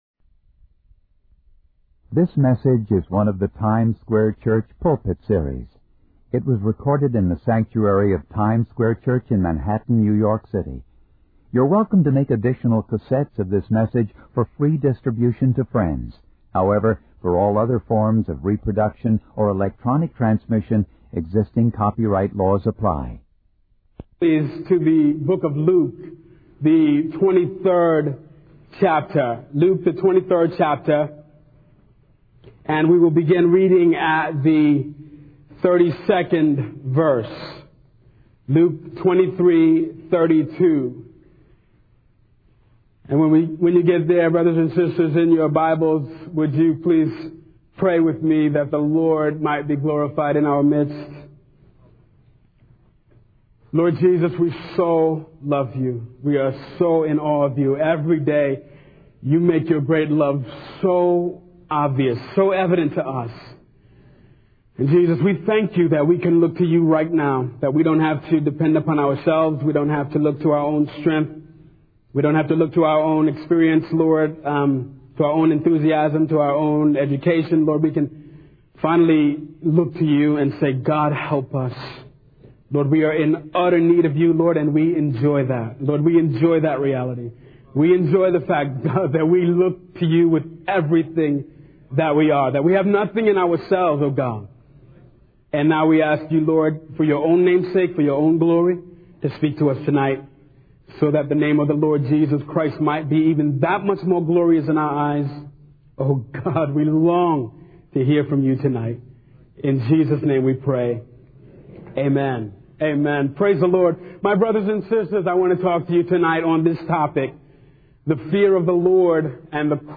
In this sermon, the preacher begins by reading from Luke 23:32, where Jesus is crucified between two criminals. He challenges the common belief that a deathbed conversion is the only way for a lifelong sinner to be saved.
It was recorded in the sanctuary of Times Square Church in Manhattan, New York City.